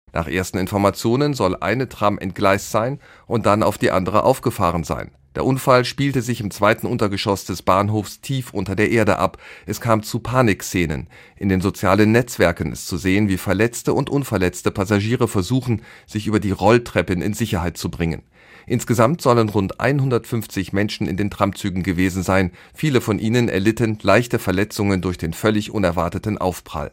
Unser Frankreich-Korrespondent